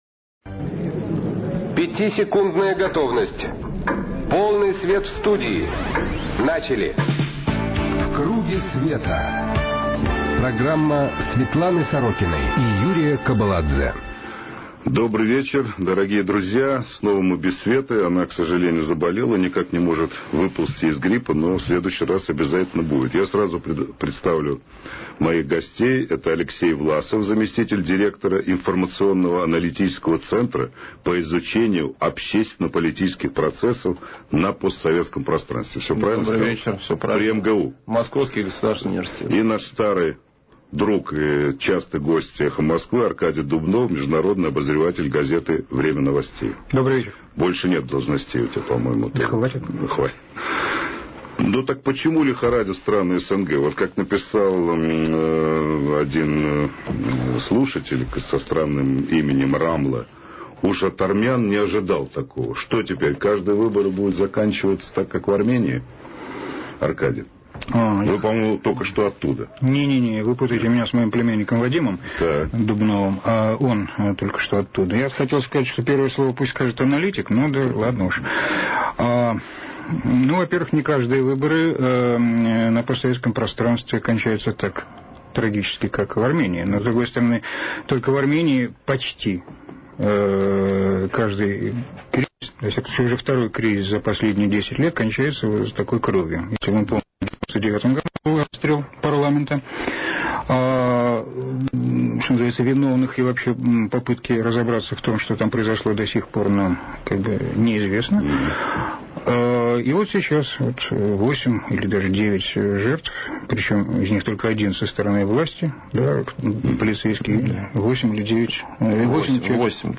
В КРУГЕ СВЕТА программа Светланы Сорокиной на радио «Эхо Москвы» соведущий - Юрий Кобаладзе 4 марта 2008 г. Почему лихорадит страны СНГ (последствия демократических выборов).